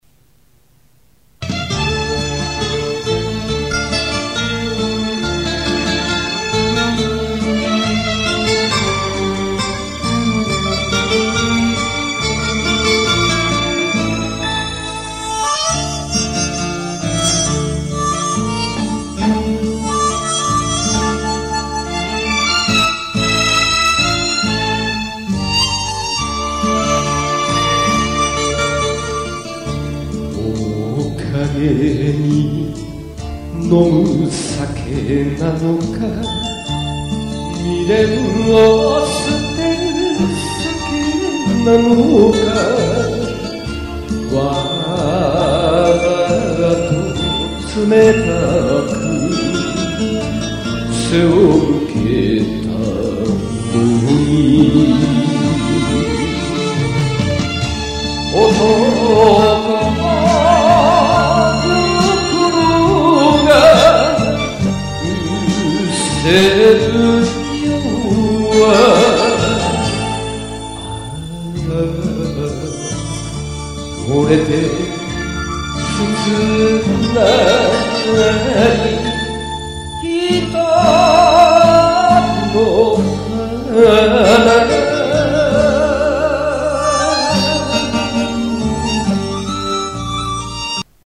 曲名をクリックしてください生バンドで歌っています。
若干のノイズが入ります(^_^;)
グランドチャンピオン大会
Windows　Media　Playerでワンコーラス流れます
テレビ放映画像からフルバンド